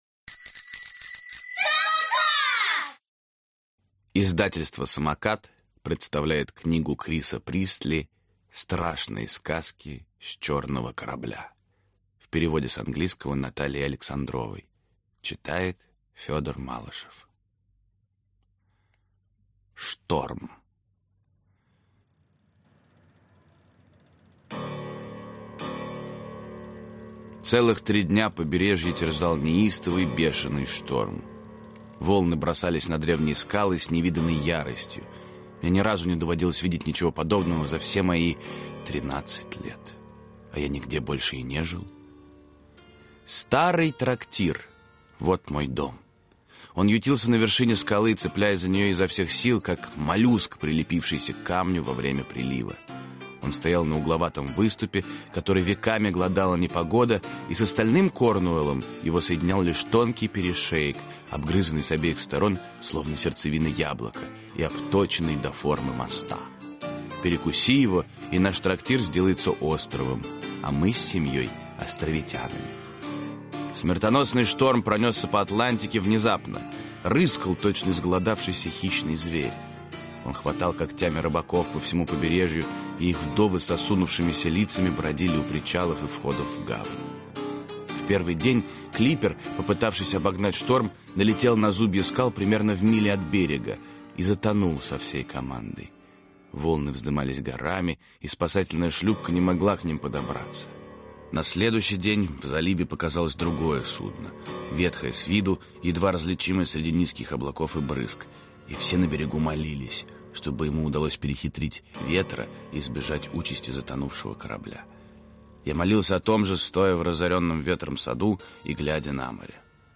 Аудиокнига Страшные сказки с Чёрного корабля | Библиотека аудиокниг